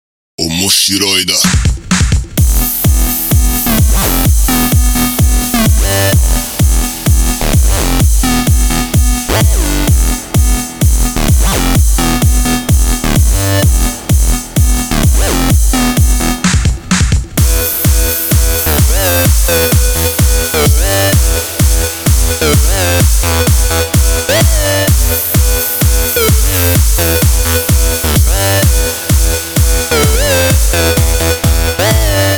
Рингтоны electro house
Клубные